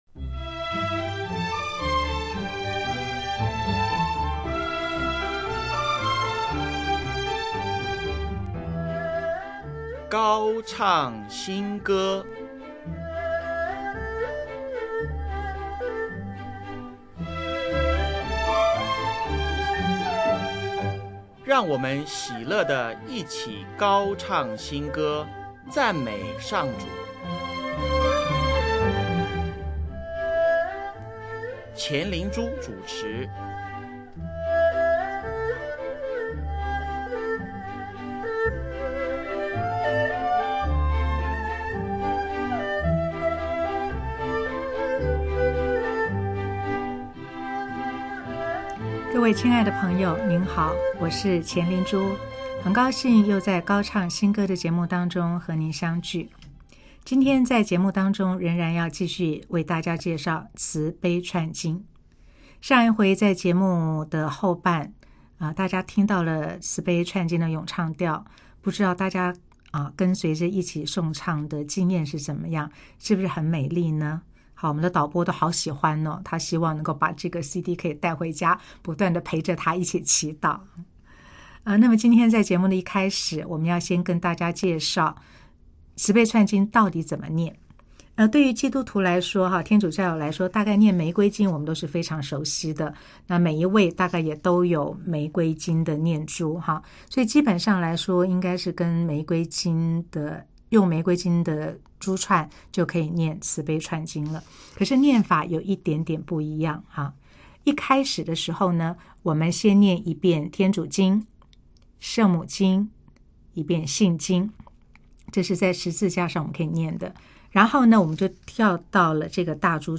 【高唱新歌】136|用歌咏调唱慈悲串经(二)：想像不到的恩宠